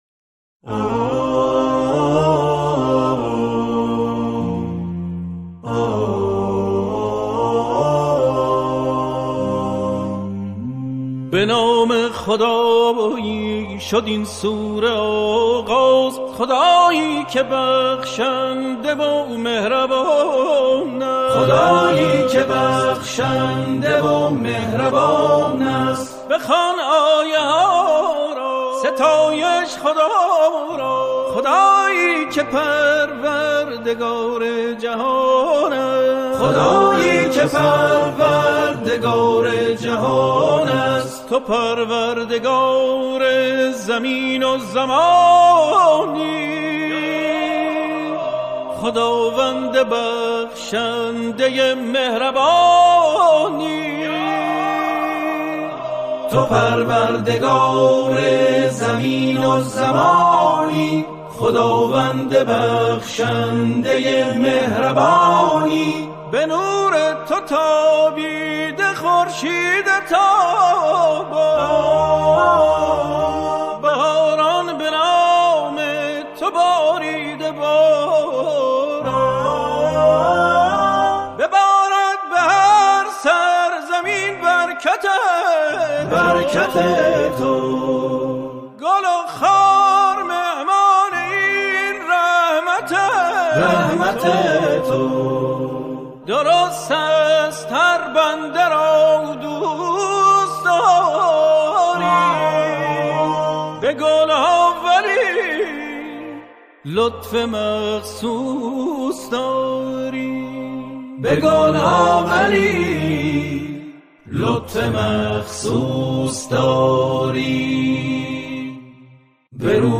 آکاپلا
گروهی از همخوانان